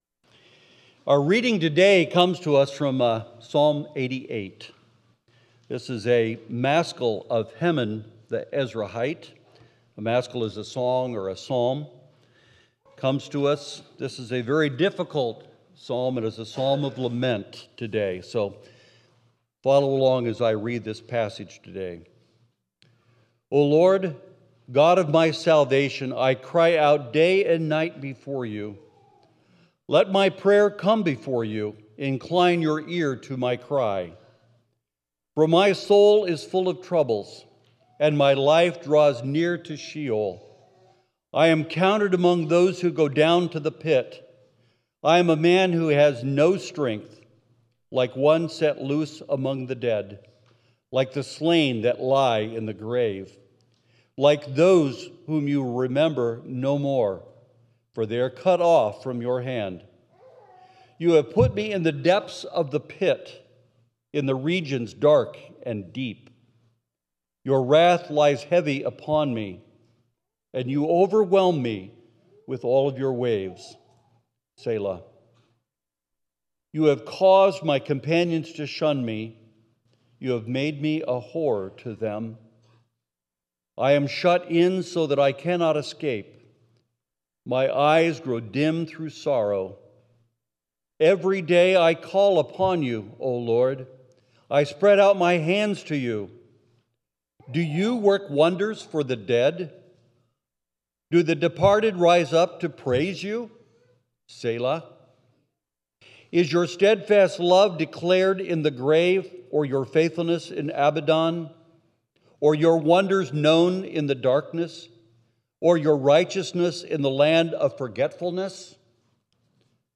7.20.25 sermon.m4a